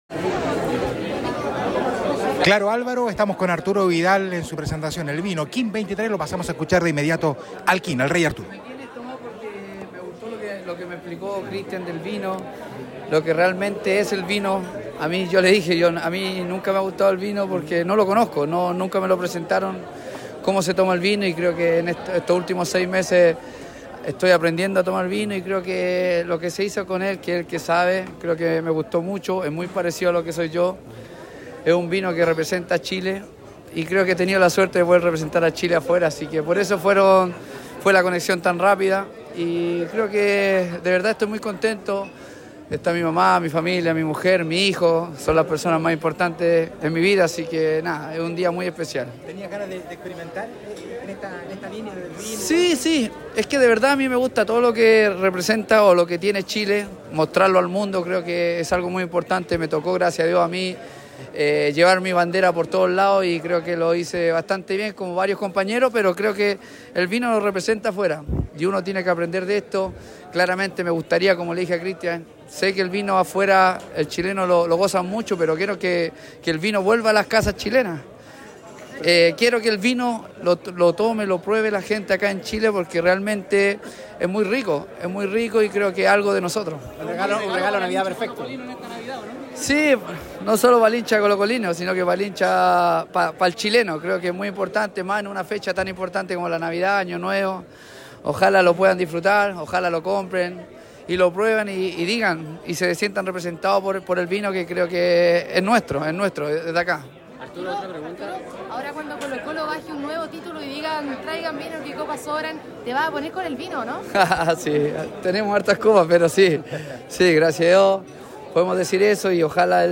En la presentación de su vino, el volante nacional también valoró el regreso a las canchas de Alexis Sánchez.